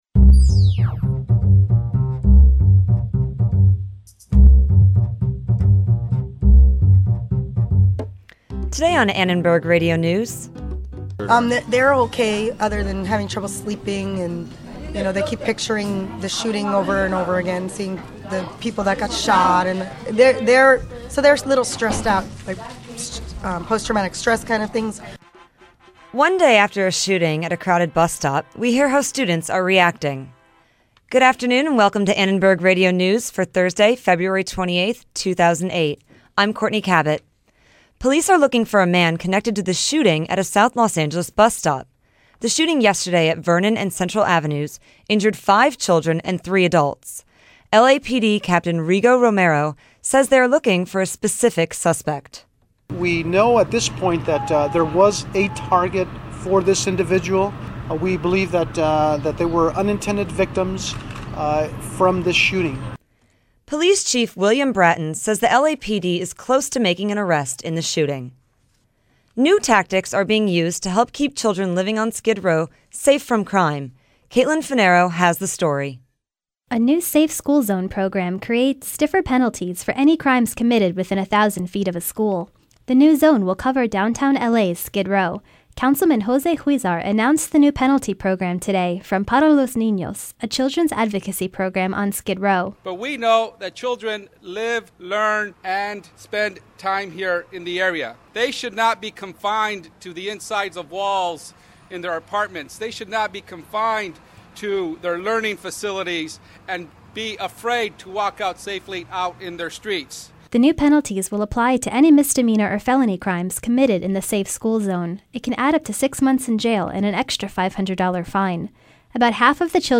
ARN Live Show - February 28, 2008 | USC Annenberg Radio News
One day after a shooting at a crowded South Los Angeles bus stop our reporter talks to the teacher of the victims to see how her students are reacting.